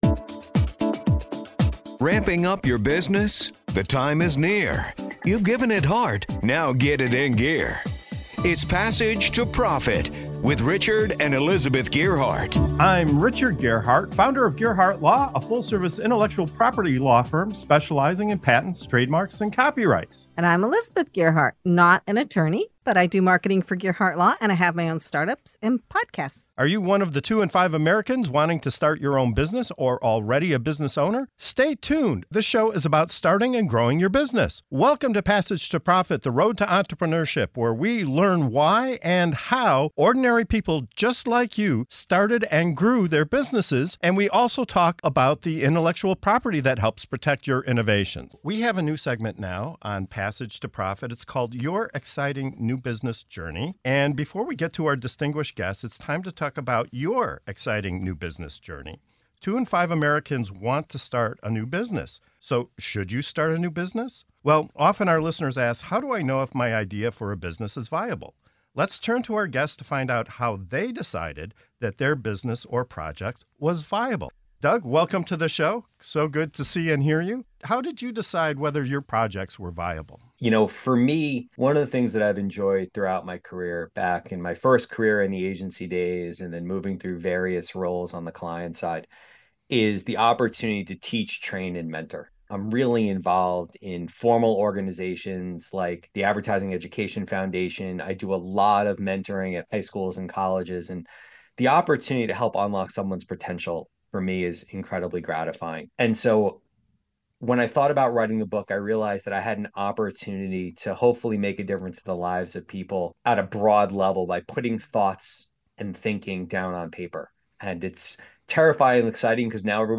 Join us as we uncover the secrets to starting and growing your own business, featuring captivating stories from ordinary individuals turned extraordinary entrepreneurs. Entrepreneurs from various industries and backgrounds discuss how they decided if their business or project was viable and harnessing the power of unmet needs in the marketplace.